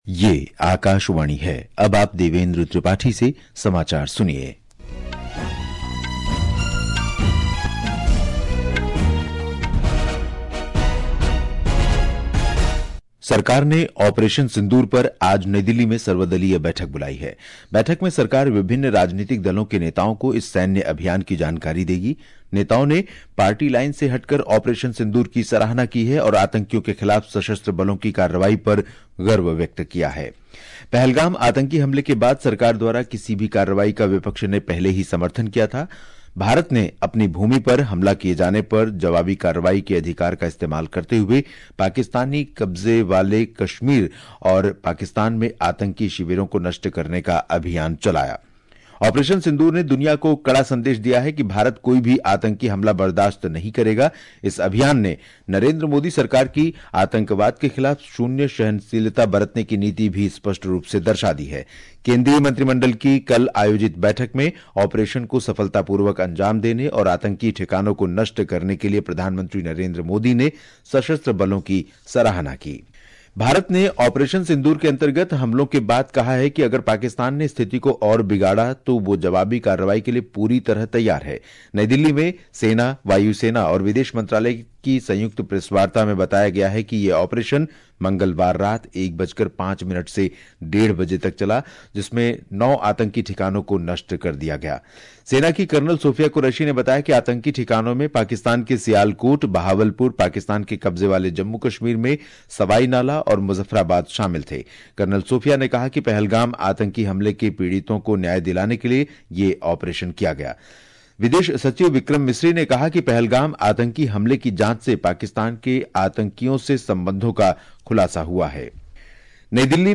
प्रति घंटा समाचार | Hindi